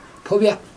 [pubiax] noun crocodile